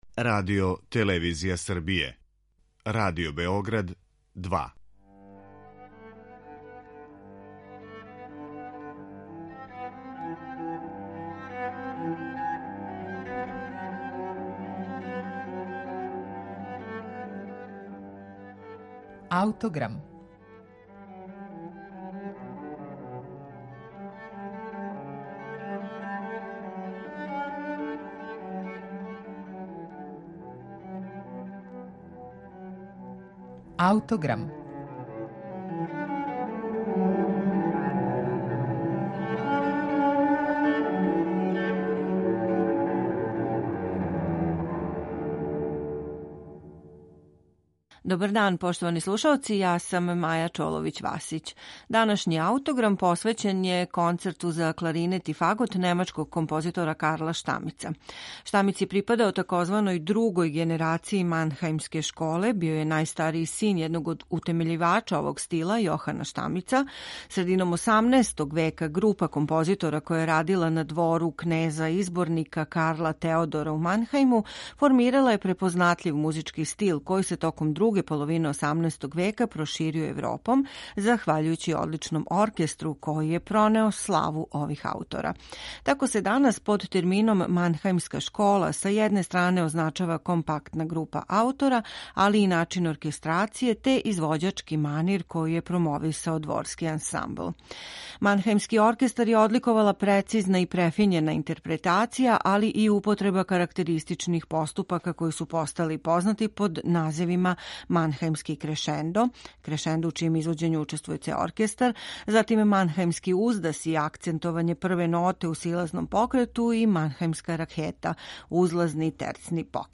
Међу њима је и Концерт за кларинет и фагот, пронађен у библиотеци принцезе Турна и Таксиса у Регенсбургу, настао највероватније пре 1780. године, којим преовлађује расположење ведрине и благог хумора. Разлика у тембру између два солистичка инструмента искоришћена је врло ефектно, а степен индивидуалности у писању њихових деоница врло је висок.
кларинетиста
фаготиста